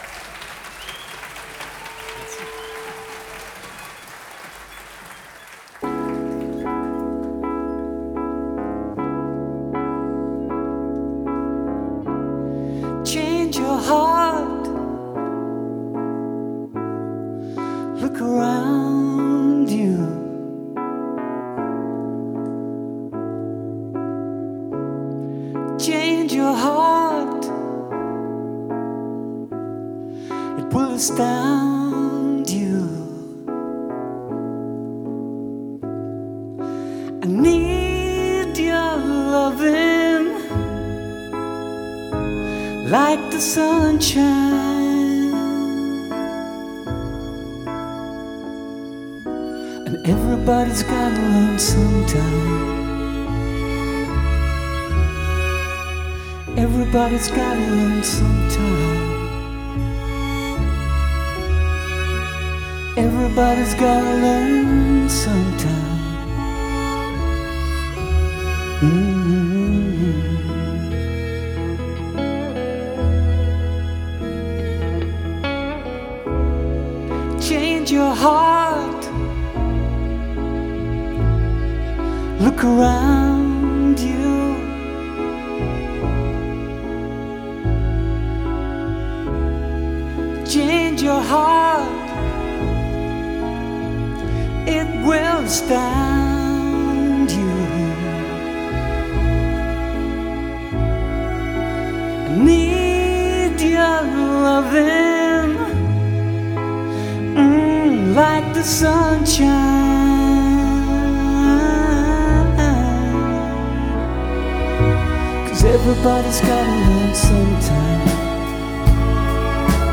SymphoPop à l’Autre Canal